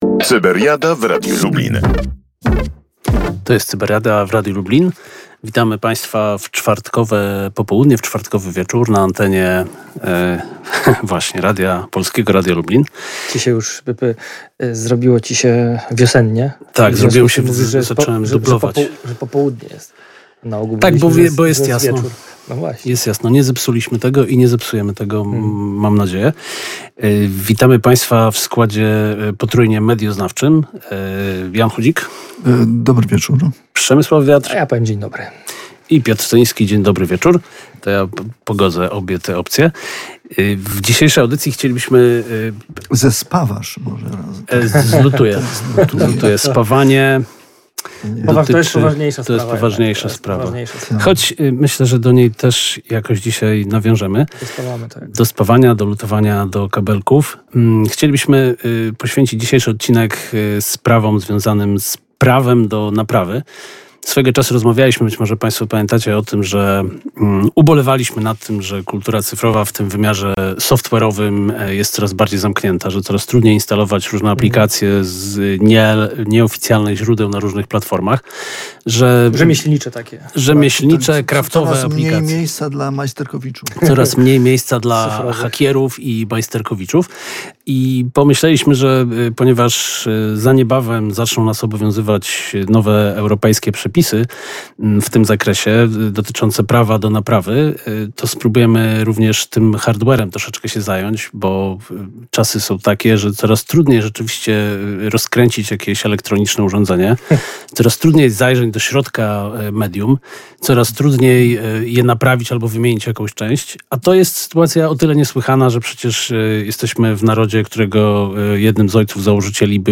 Najbliższa Cyberiada DIY już w czwartek (16.04) po 18:00, na żywo tylko w Radiu Lublin.